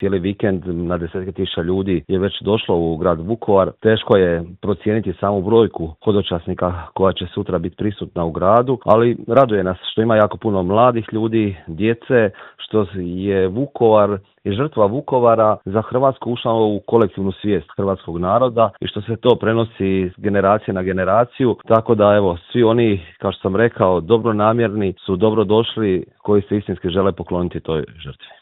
Vukovarski gradonačelnik Marijan Pavliček u Intervjuu Media servisa kaže da je grad već danima pun hodočasnika: